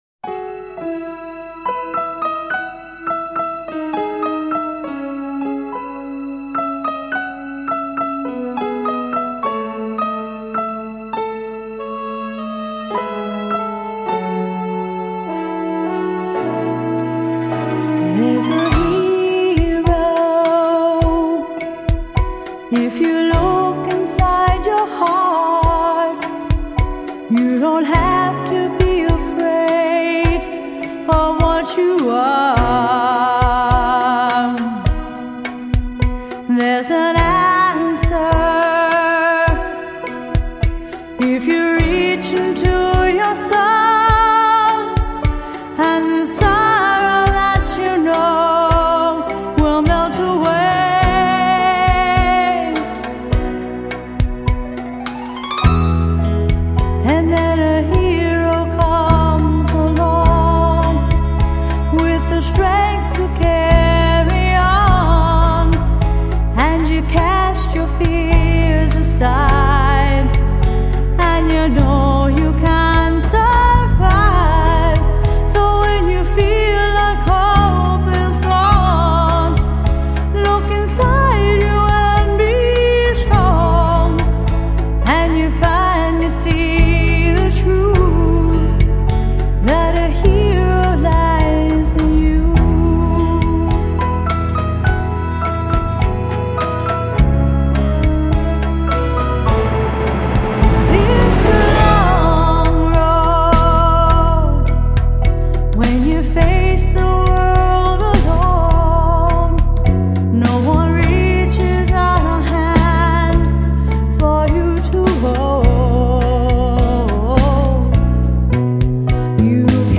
10 Rumba